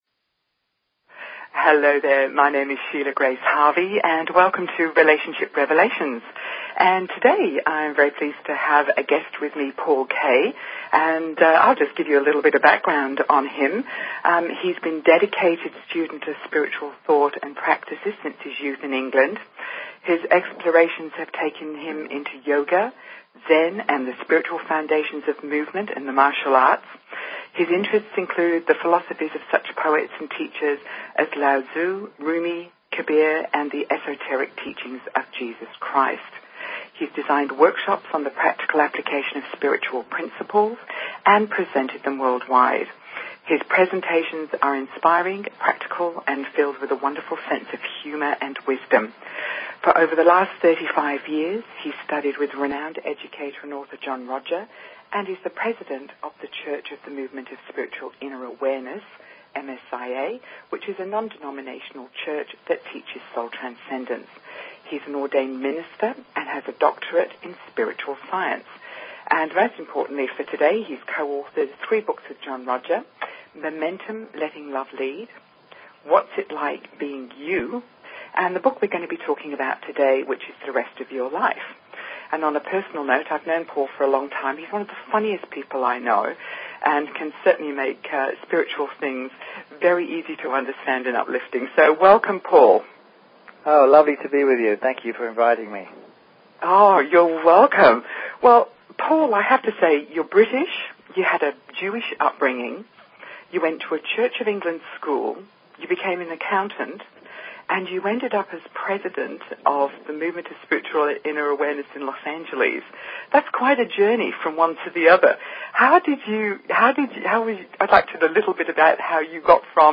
Talk Show Episode, Audio Podcast, Relationship_Revelations and Courtesy of BBS Radio on , show guests , about , categorized as